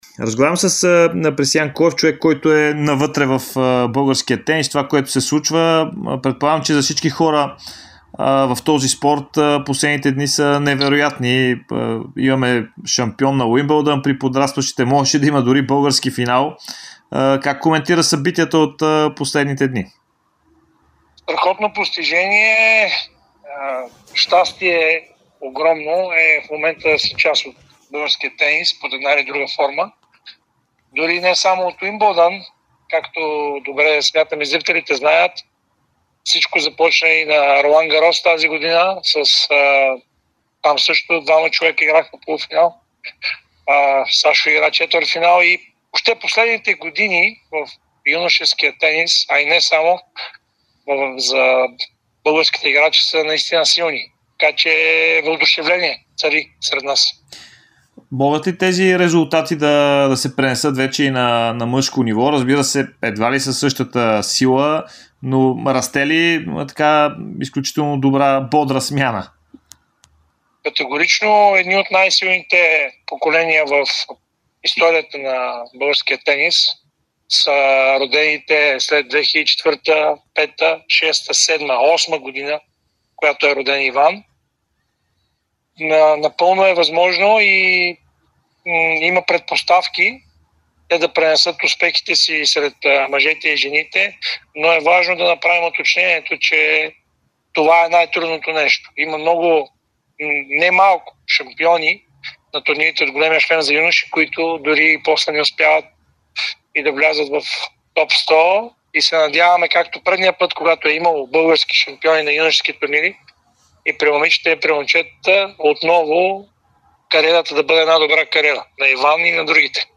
Специалистът говори специално за Подкаст в ефира по Дарик радио.